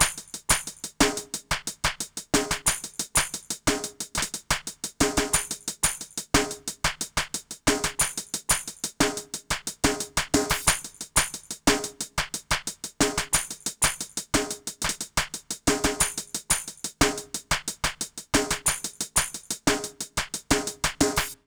British REGGAE Loop 090BPM (NO KICK).wav